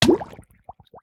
Minecraft Version Minecraft Version snapshot Latest Release | Latest Snapshot snapshot / assets / minecraft / sounds / entity / player / hurt / drown2.ogg Compare With Compare With Latest Release | Latest Snapshot
drown2.ogg